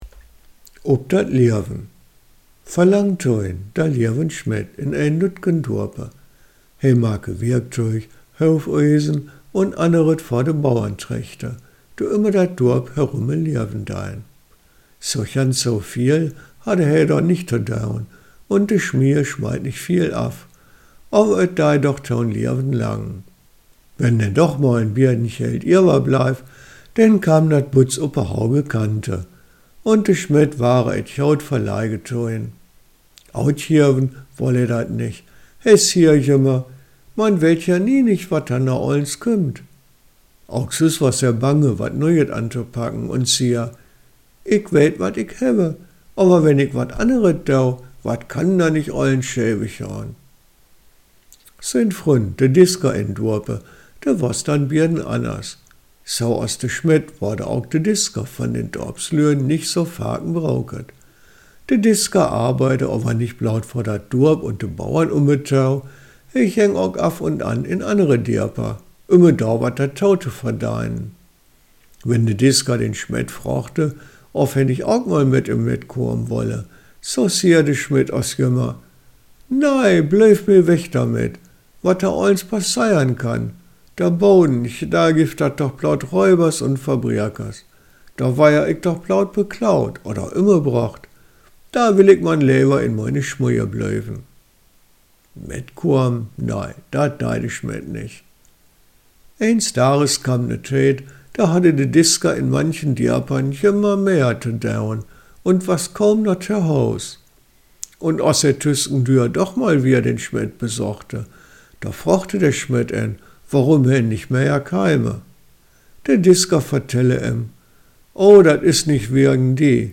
Humorvolle kleine Geschichten im Bielefelder Plattdeutsch aus Ostwestfalen.